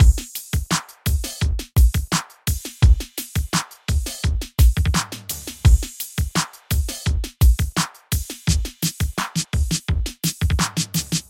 三层鼓
描述：3个独立的鼓，很好地结合在一起 16个节拍，包括填充物85 bpm
Tag: 85 bpm Hip Hop Loops Drum Loops 1.90 MB wav Key : Unknown